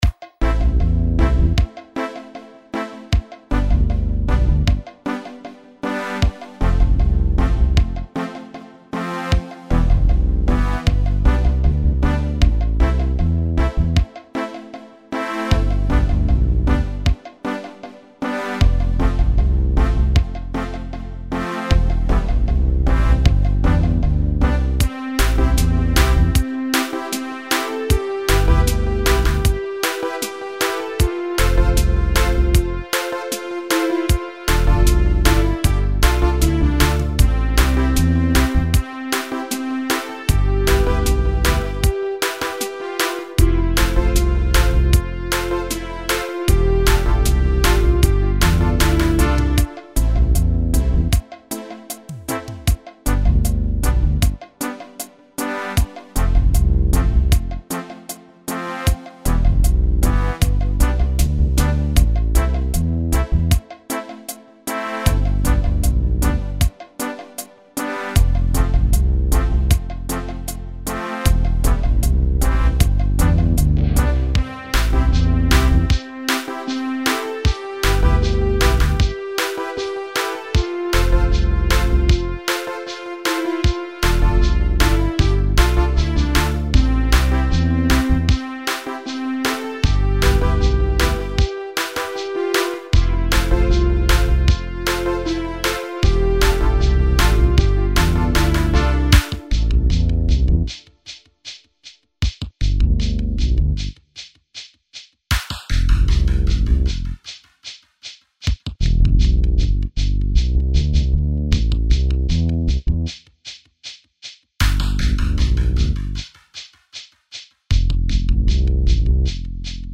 BGM
ロング暗い民族